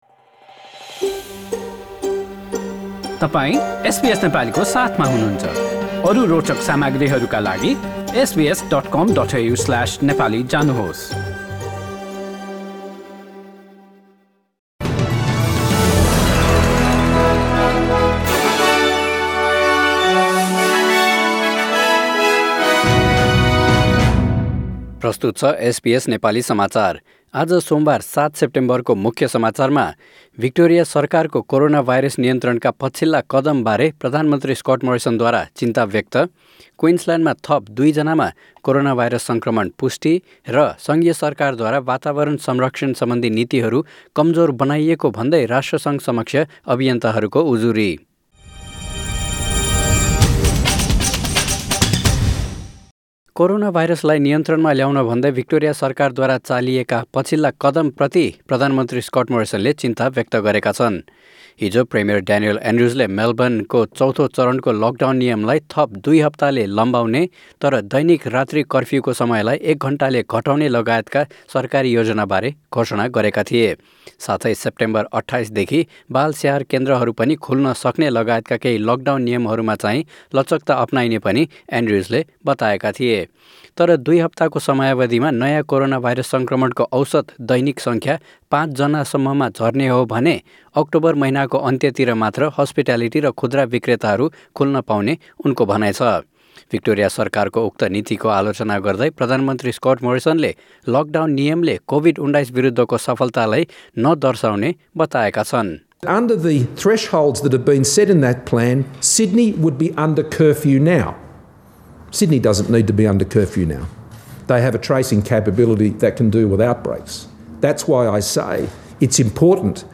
एसबीएस नेपाली अस्ट्रेलिया समाचार: सोमबार ७ सेप्टेम्बर २०२०